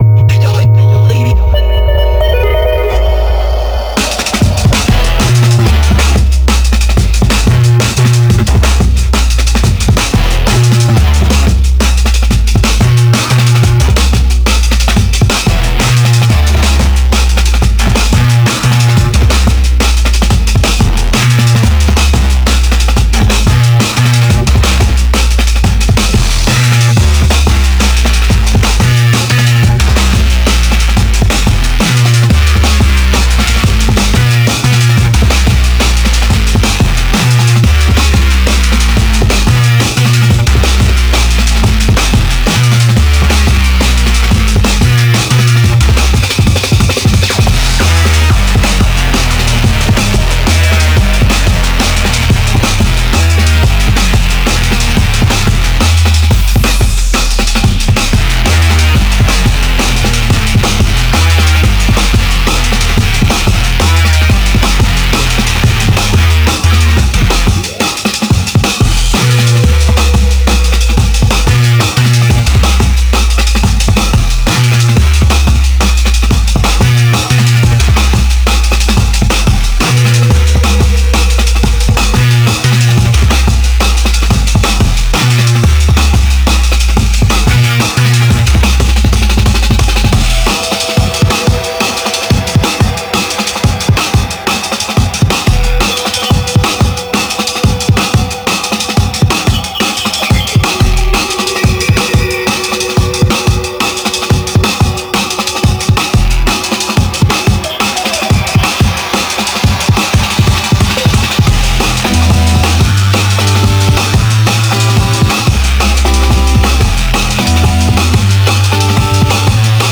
Genre Jungle